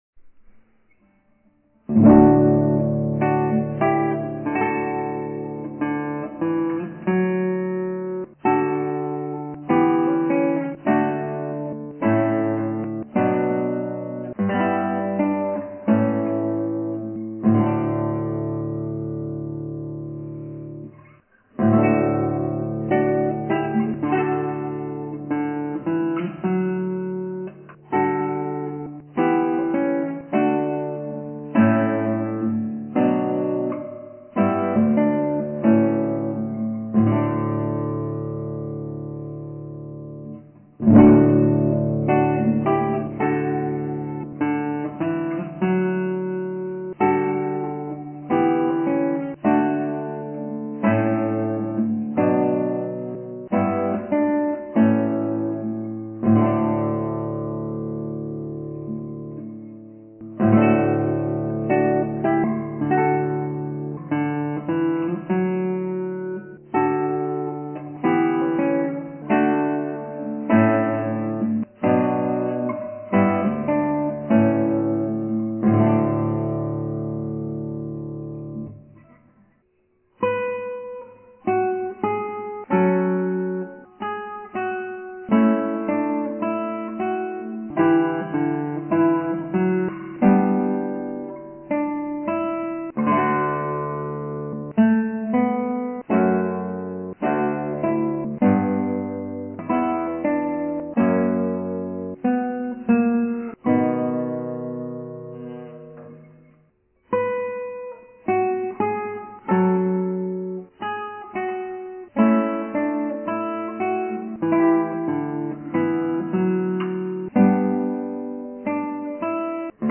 ギターはアントニオマリン